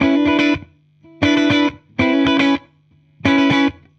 Index of /musicradar/dusty-funk-samples/Guitar/120bpm
DF_70sStrat_120-E.wav